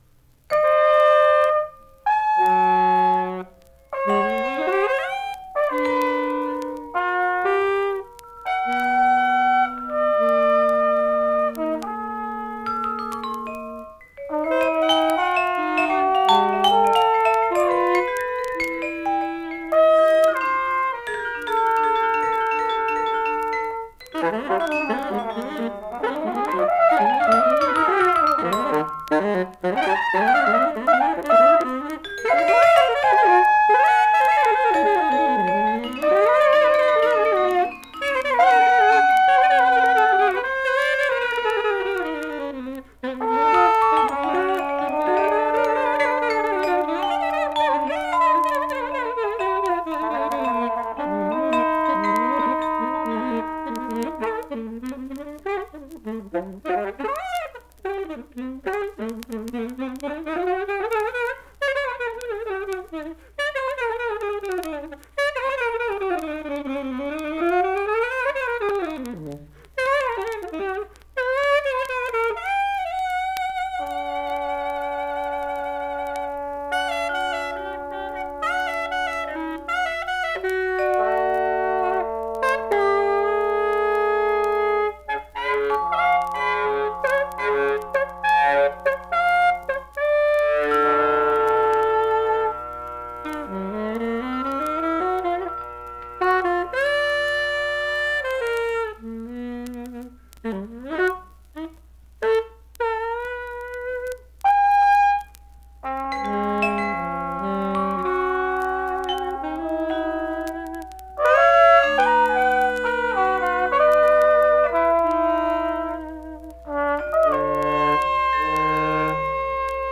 Vibraphone, Bells